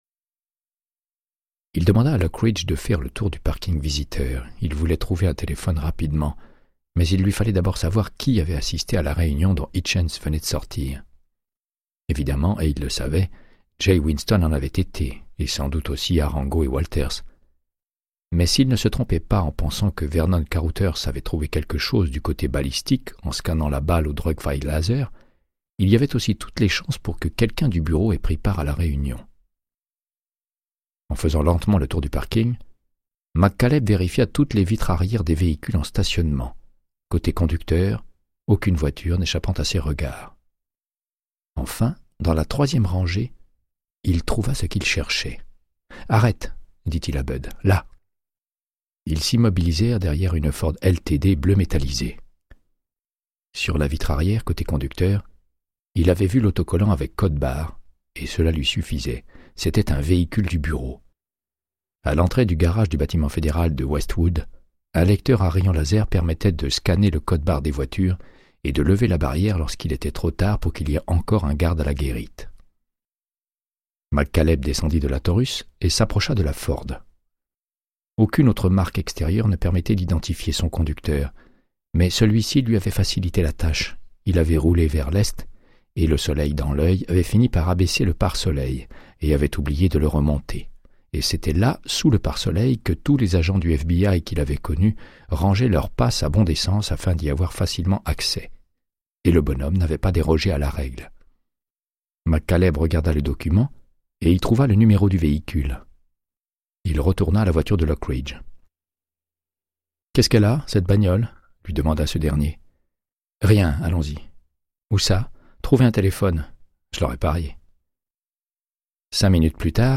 Audiobook = Créance de sang, de Michael Connelly - 96